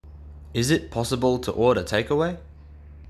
ネィティヴの音声を録音したので、雰囲気を感じて下さいね。